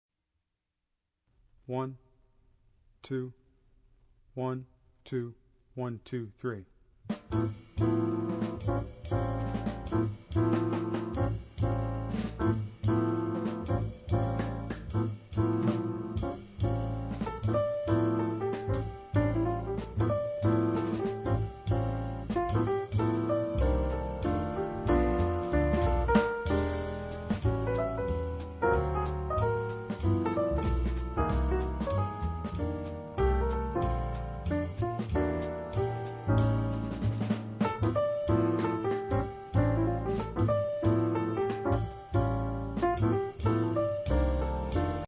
Obsazení: Flöte